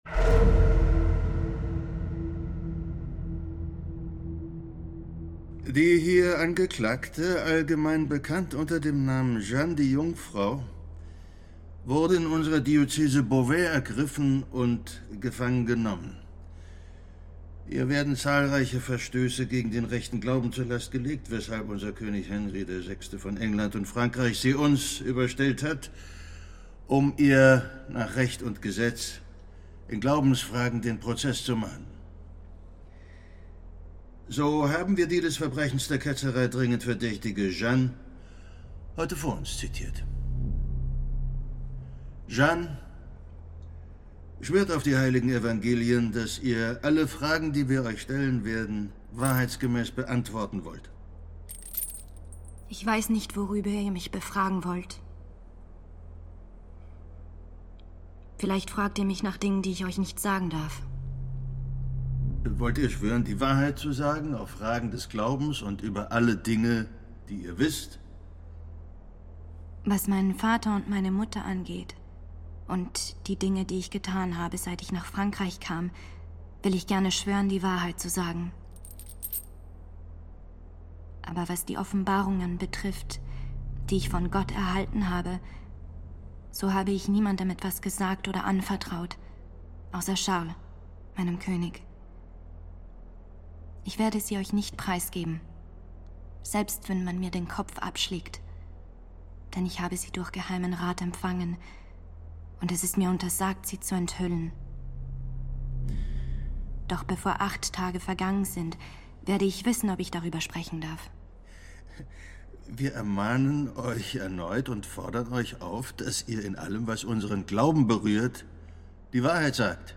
Radio | Hörspiel
Komposition: alva noto
Produktion: Südwestrundfunk mit dem Rundfunk Berlin Brandenburg 2023